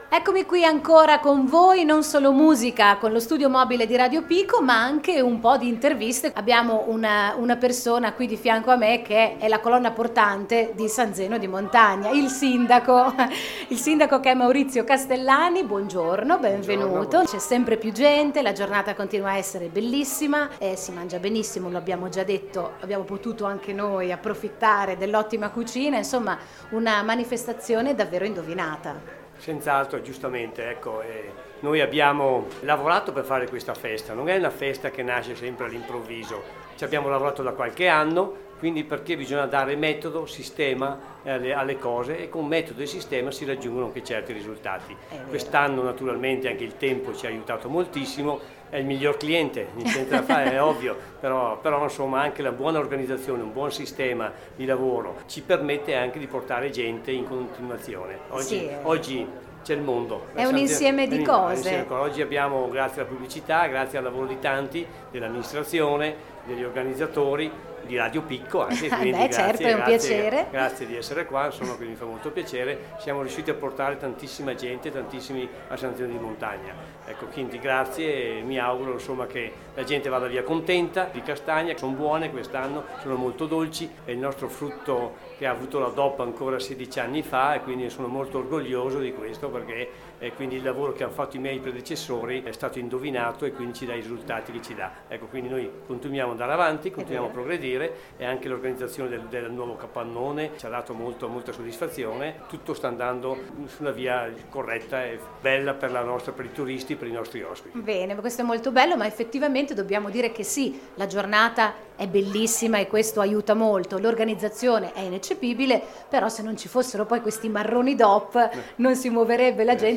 In collegamento dallo studio mobile
Maurizio Castellani, sindaco di San Zeno